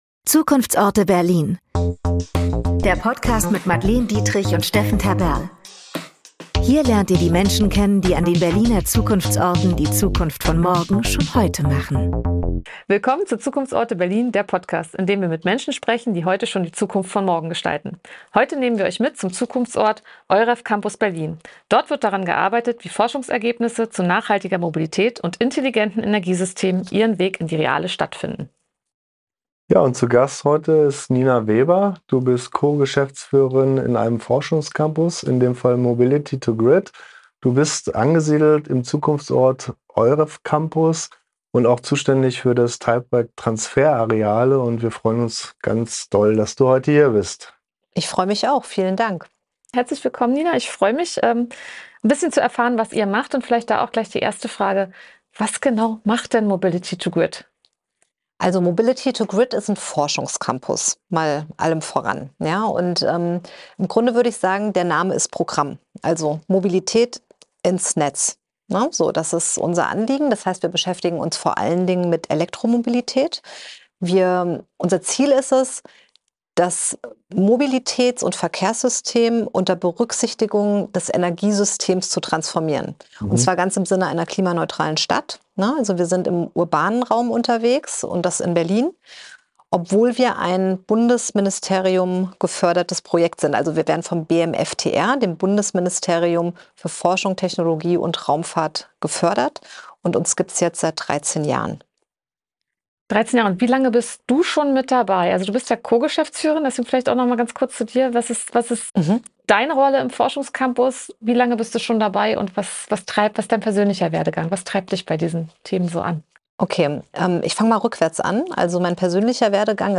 Ein Gespräch über urbane Energiewende, Systemintegration, Netzstabilität, politische Rahmenbedingungen und die Frage, wie Berlin bis 2030 klimaneutrale Mobilität Realität werden lassen kann.